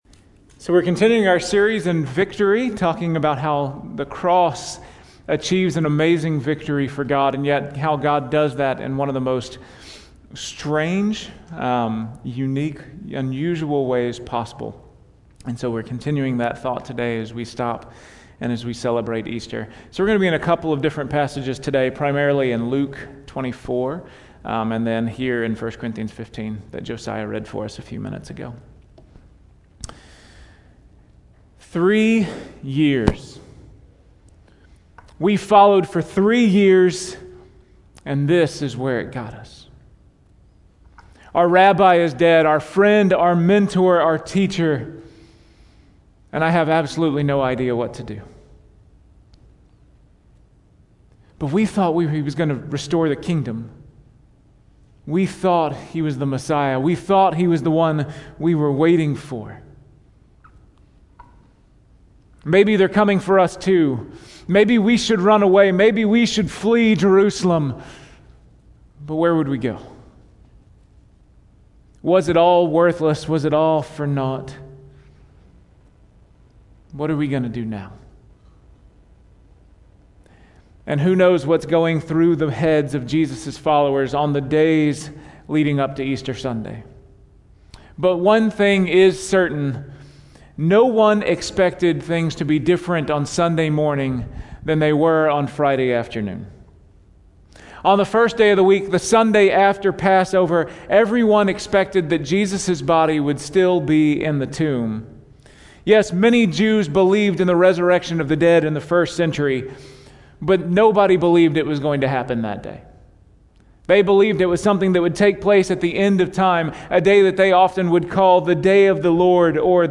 Weekly Sermon Audio “Victory Over Death!”